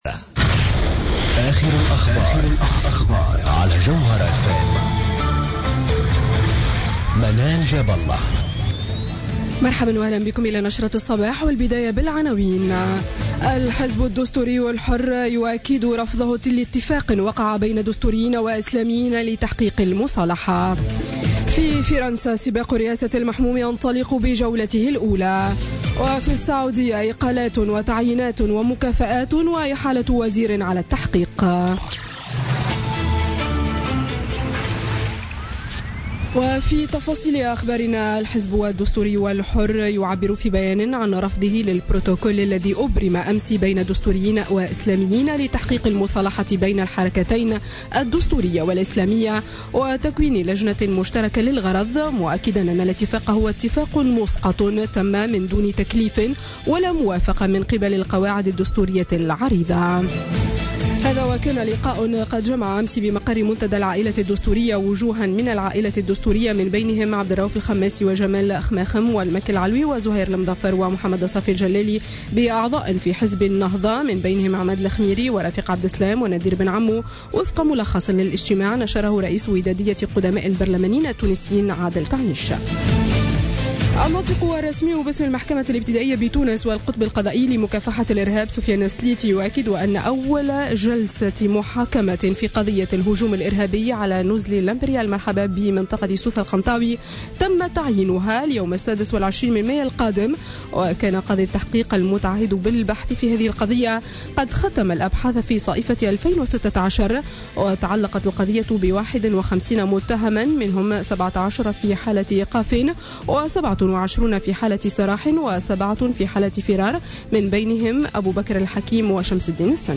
نشرة أخبار السابعة صباحا ليوم الأحد 23 أفريل 2017